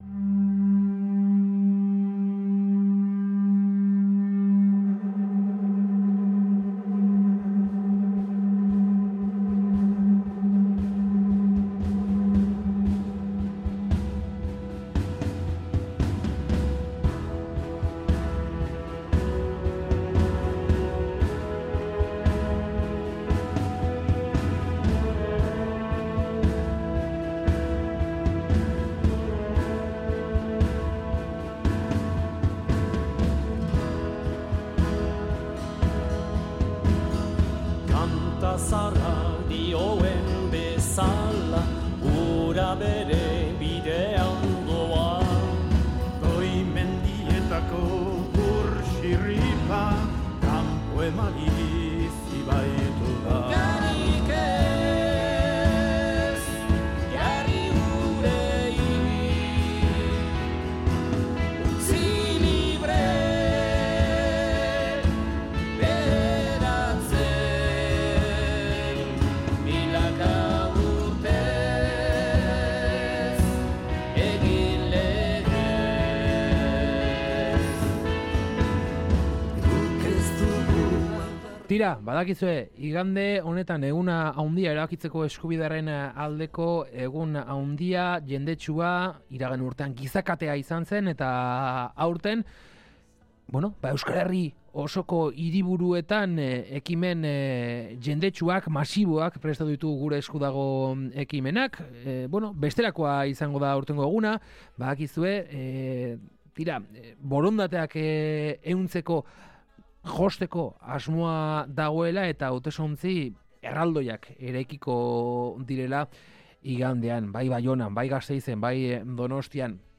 Gaur giroari termometroa jarri nahi izan diogu Kalegorrian saioan eta horretarako Oñatiko, Aguraineko eta Izpurako hiru lagunekin hitz egin dugu. Hiruak buru-belarri ari dira Gure Esku Dago ekimen herritarraren baitan lanean.